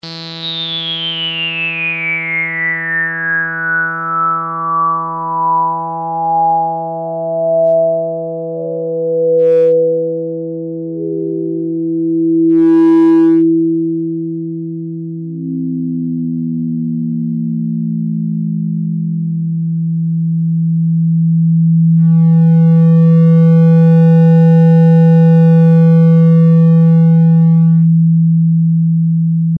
› Basic RESO SWEEP + max Resonance.mp3
Basic_RESO_SWEEP+maxResonance.mp3